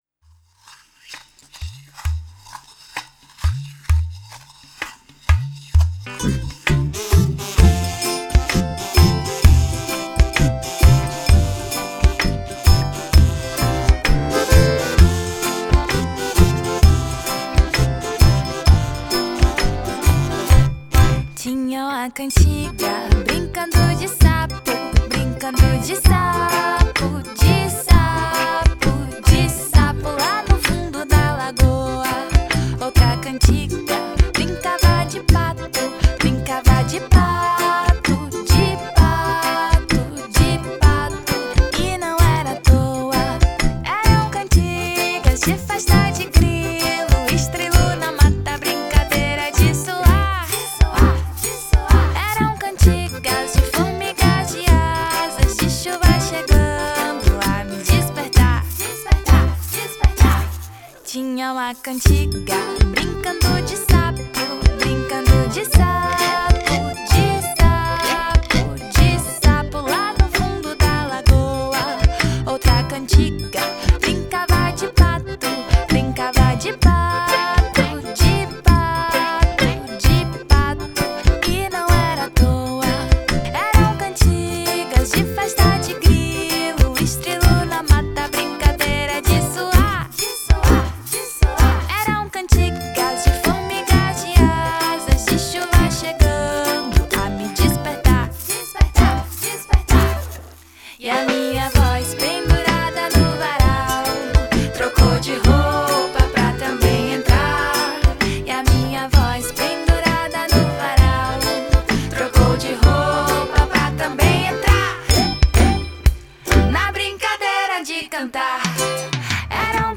Acordeon.